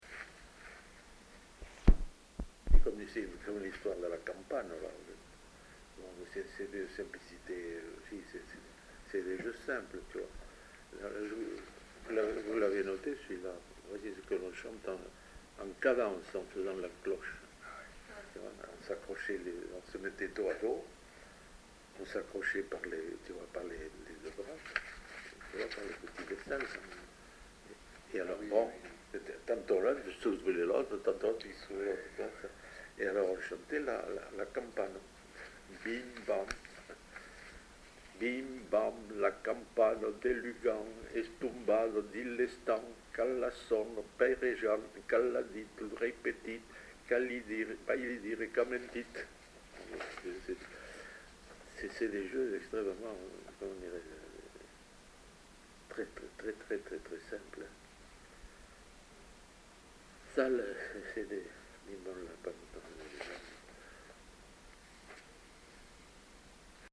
Lieu : Saint-Sauveur
Genre : forme brève
Effectif : 1
Type de voix : voix d'homme
Production du son : chanté
Classification : formulette enfantine